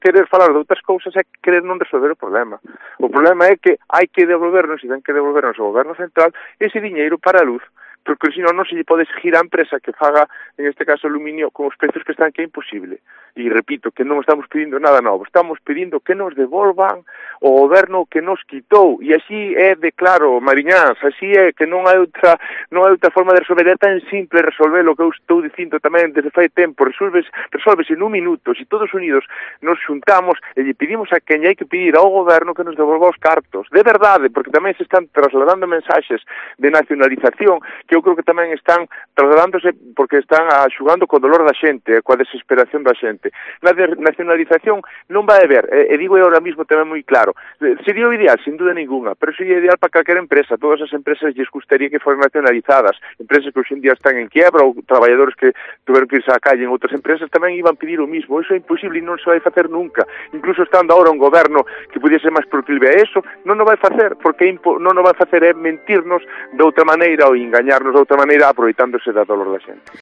Declaraciones del alcalde de Cervo sobre la nacionalización de Alcoa